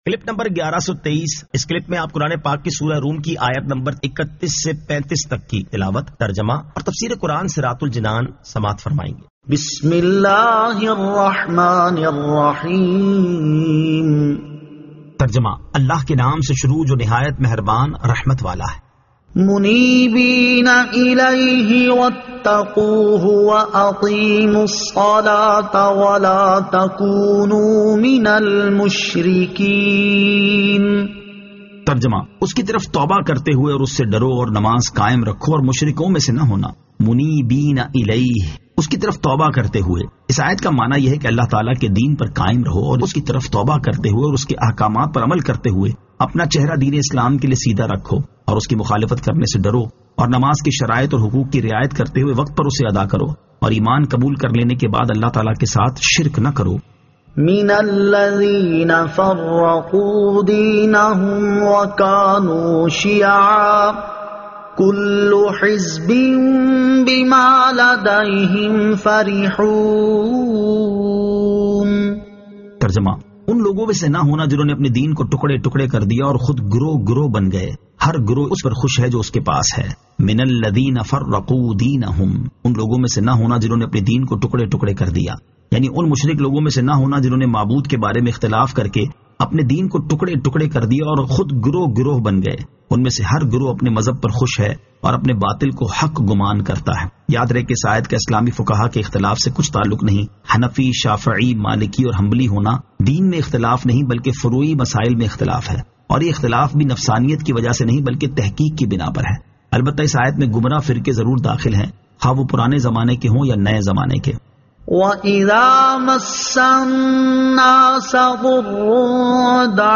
Surah Ar-Rum 31 To 35 Tilawat , Tarjama , Tafseer